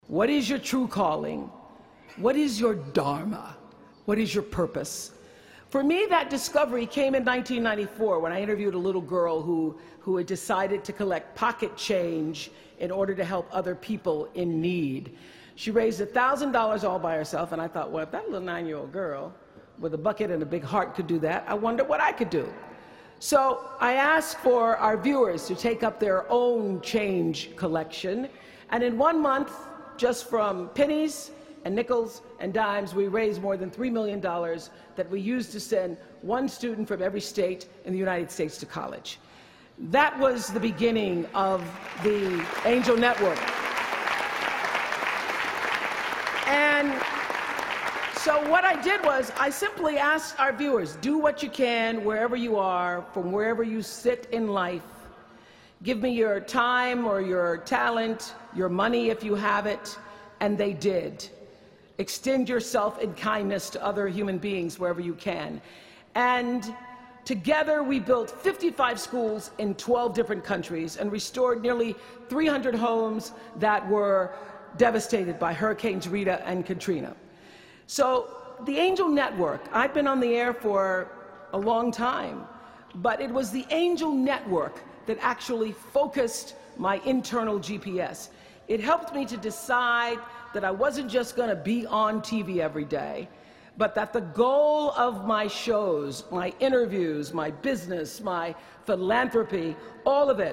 公众人物毕业演讲第359期:奥普拉2013在哈佛大学(8) 听力文件下载—在线英语听力室